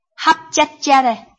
臺灣客語拼音學習網-客語聽讀拼-南四縣腔-入聲韻
拼音查詢：【南四縣腔】jiad ~請點選不同聲調拼音聽聽看!(例字漢字部分屬參考性質)